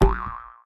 reward_drop_04.ogg